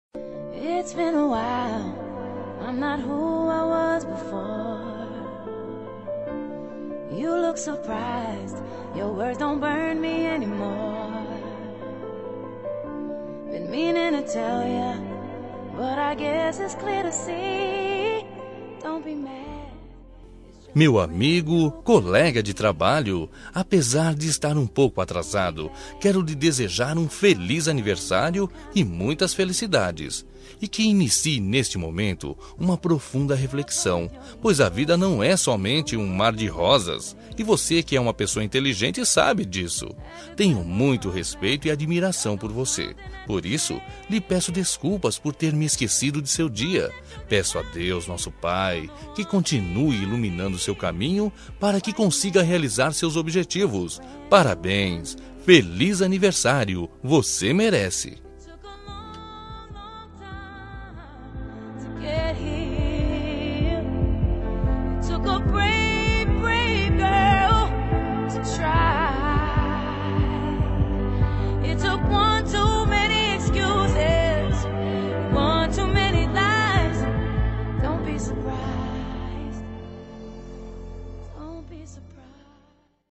Voz Masculina
Código: 01232 – Música: Brand New Me – Artista: Alicia Keys